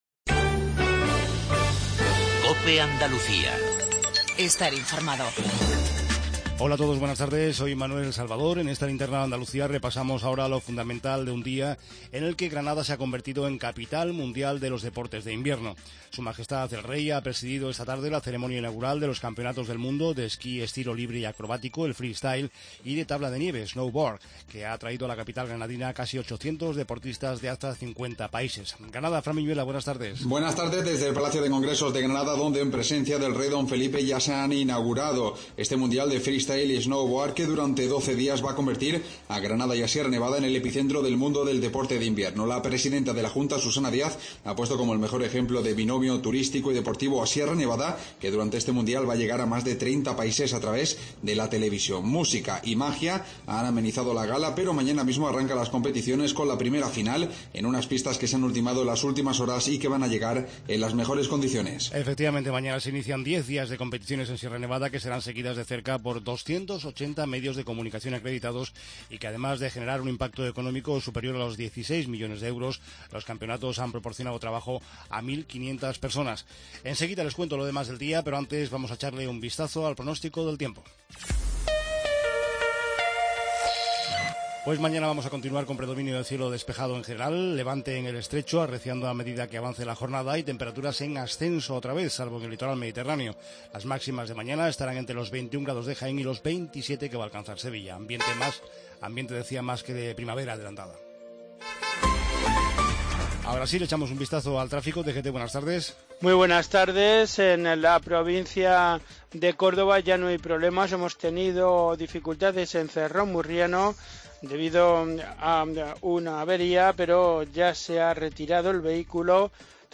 INFORMATIVO REGIONAL TARDE